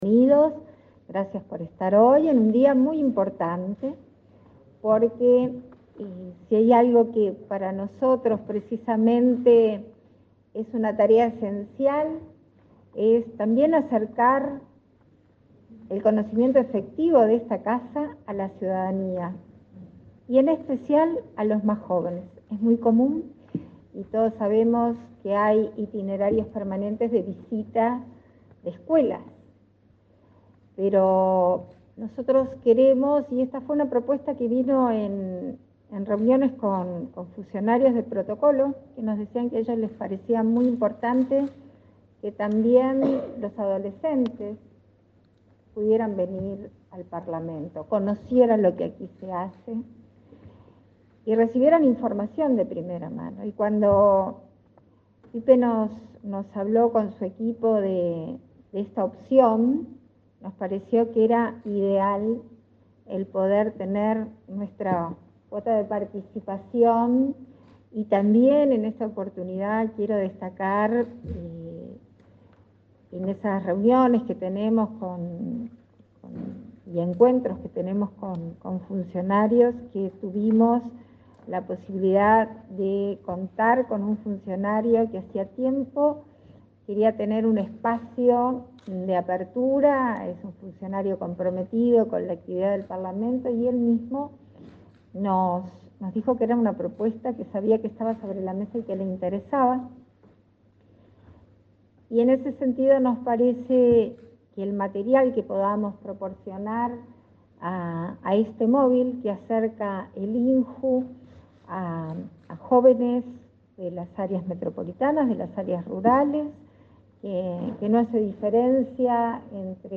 Palabras de la vicepresidenta de la República, Beatriz Argimón
Palabras de la vicepresidenta de la República, Beatriz Argimón 24/03/2022 Compartir Facebook X Copiar enlace WhatsApp LinkedIn La vicepresidenta de la República, Beatriz Argimón, destacó la importancia del INJU Móvil, un dispositivo que recorrerá todo el país para brindar información sobre actividades y talleres a adolescentes y jóvenes. El servicio fue presentado este jueves 24 en Montevideo por el Mides y el Parlamento.